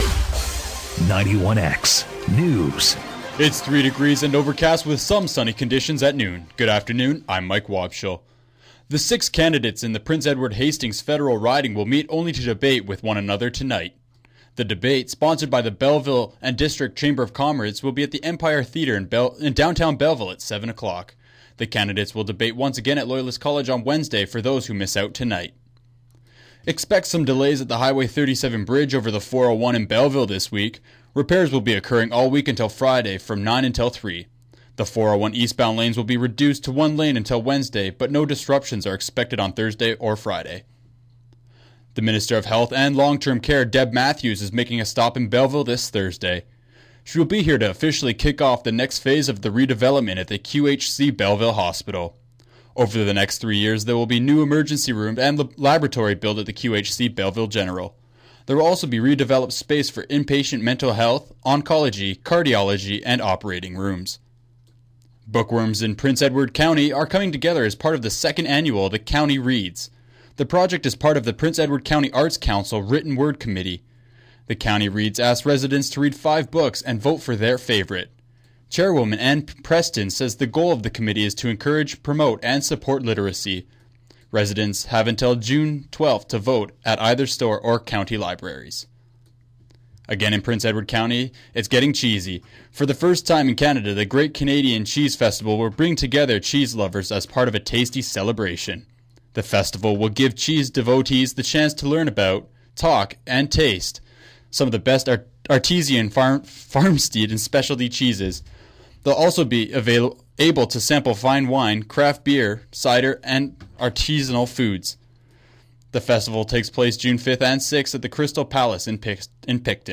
91X News April 19, 2011 12 p.m